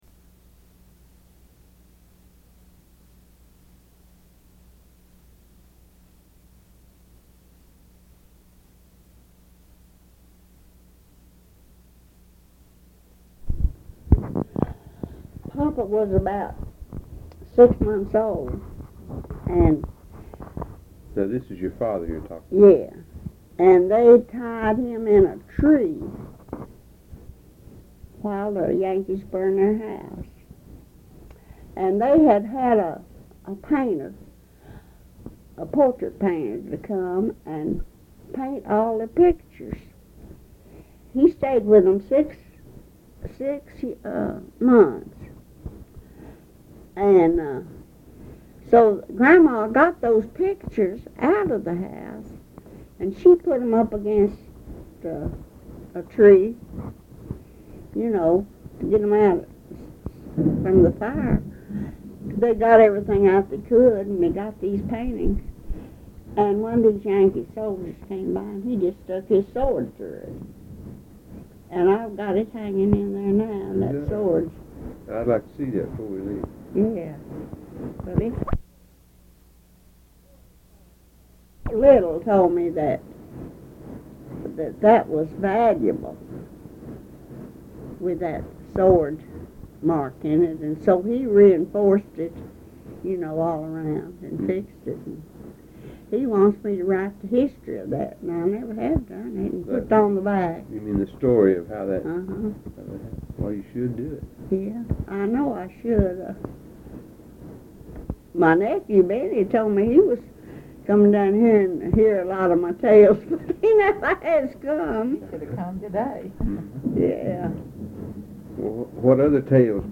Part of Oral history interview